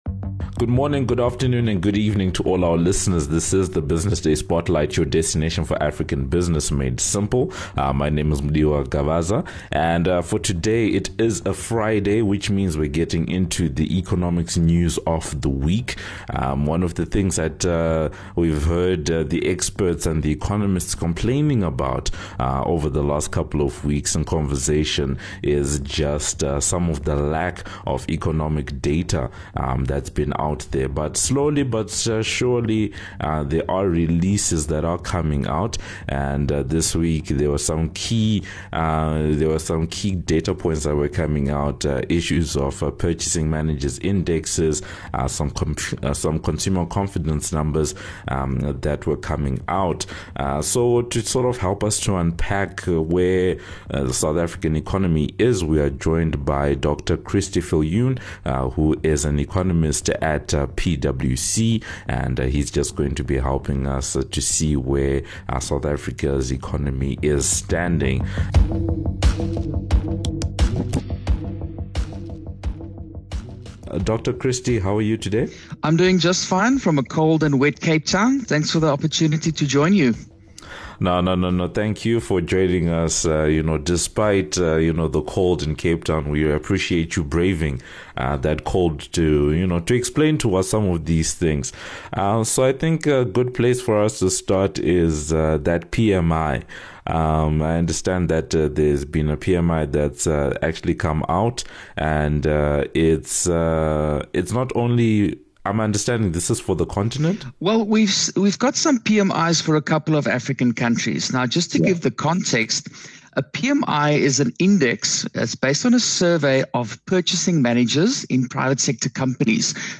Listen in to hear the full discussion and thoughts around these and other questions.